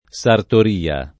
sartoria [ S artor & a ] s. f.